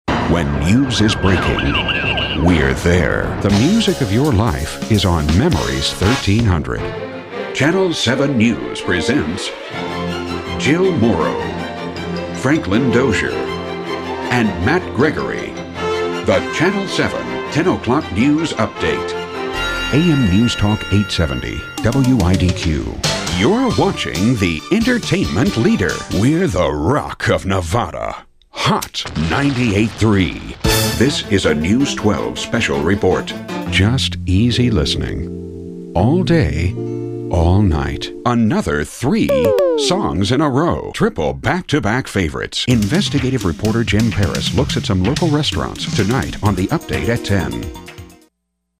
Benevolent, clean, polished, mature
Commercial Voiceover, Radio Imaging